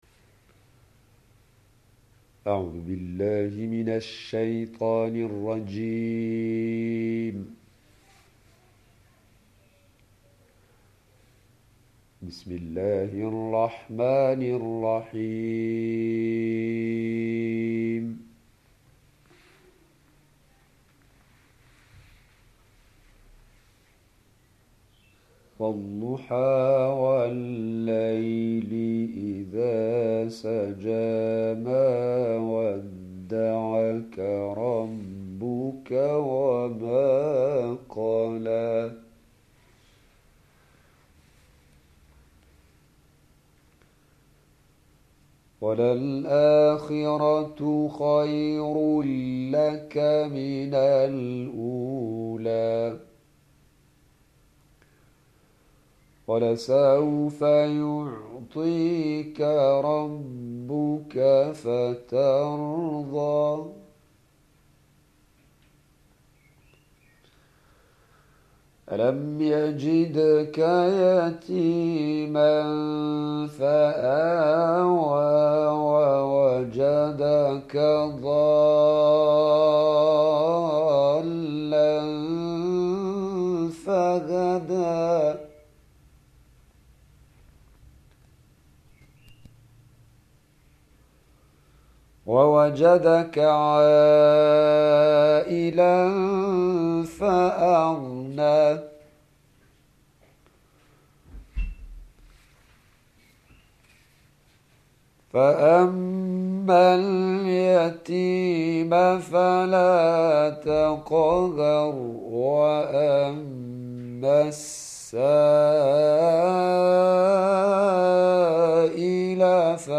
Récitations de passages du Coran en mode Tartîl (Mujawwad)
Récitation faite fait en muharram 1434 / décembre 2012 :